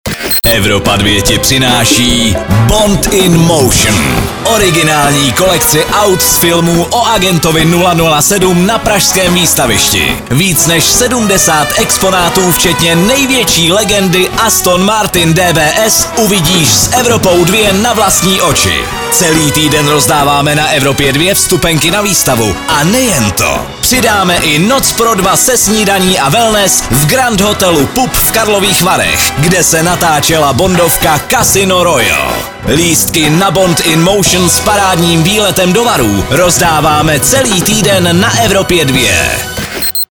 liner_bond_in_motion.mp3